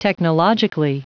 Prononciation du mot technologically en anglais (fichier audio)
technologically.wav